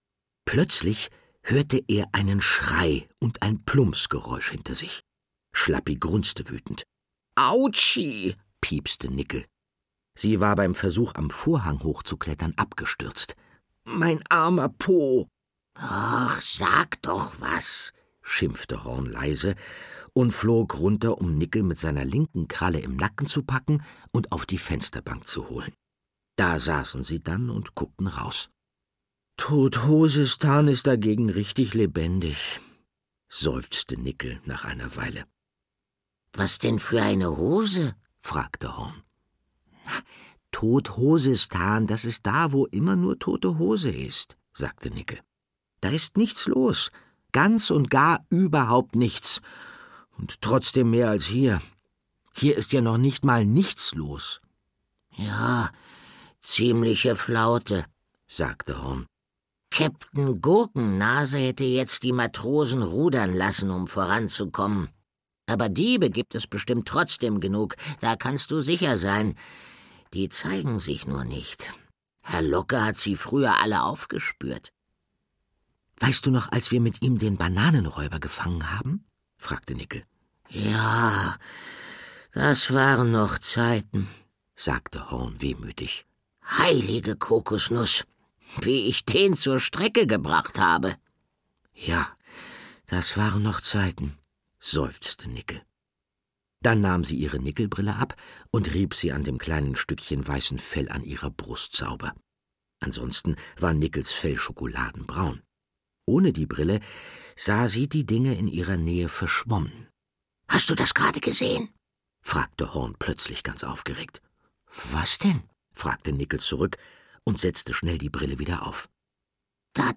Nickel & Horn 1: Nickel & Horn - Florian Beckerhoff - Hörbuch